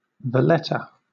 Valletta (/vəˈlɛtə/